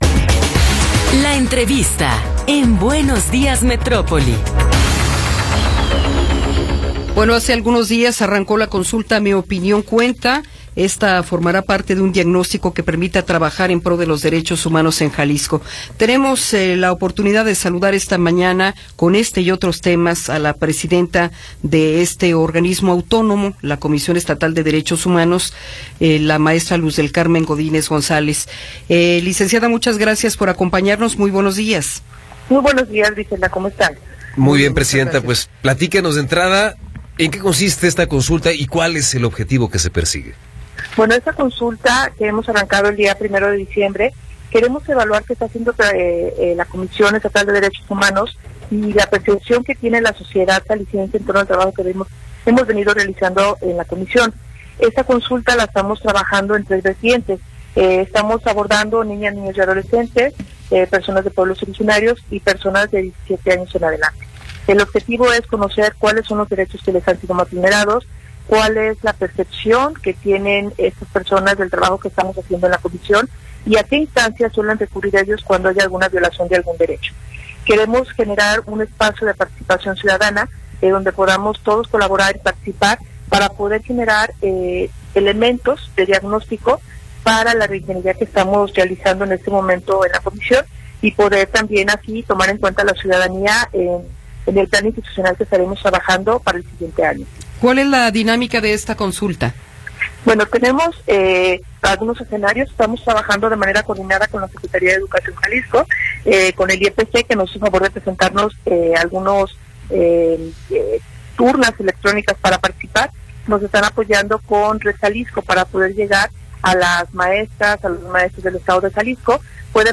Entrevista con Luz del Carmen Godínez González